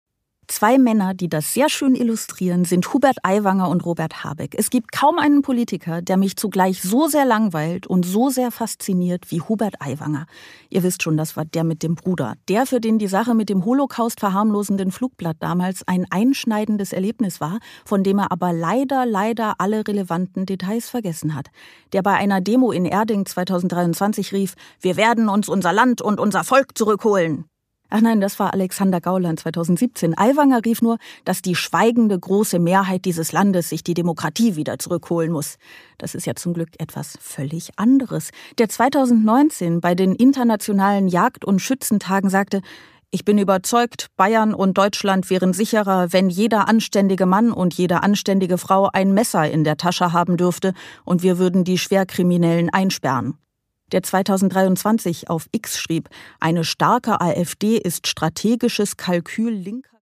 Produkttyp: Hörbuch-Download
Gelesen von: Sarah Bosetti